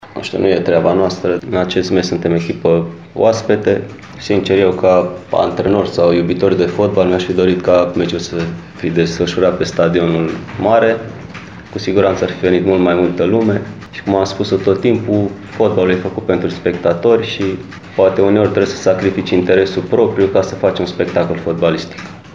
La conferința de presă de astăzi, alb-violeții au declarat că și-ar fi dorit ca meciul să se desfășoare pe stadionul „Dan Păltinișanu”, acolo unde numărul spectatorilor ar fi fost cu siguranță mai mare.